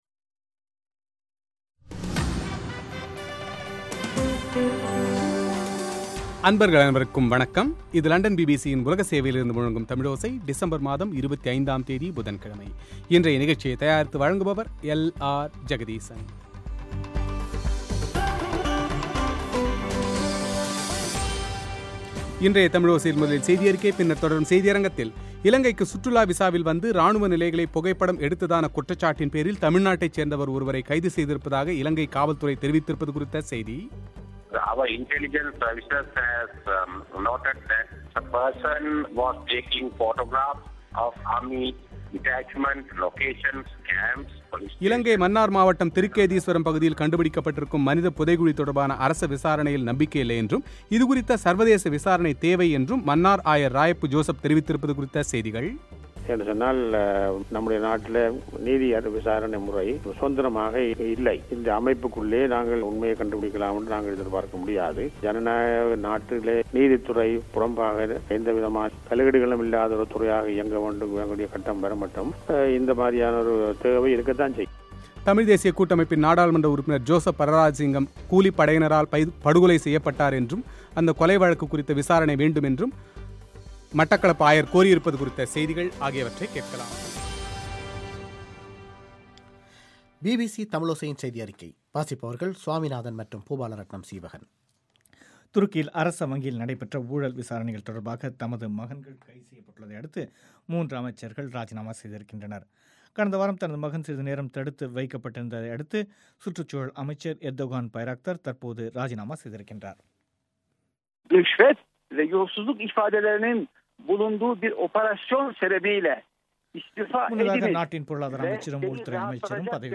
ஆசியாவில் கடந்த காலங்களில் பலவிதமான சவால்களை எதிர்கொண்ட பல நாடுகளில் நத்தார் இந்த ஆண்டு அங்குள்ள மக்களால் எவ்வாறு எதிர்கொள்ளப்பட்டது என்பது குறித்த பிபிசியின் செய்தித்தொகுப்பு;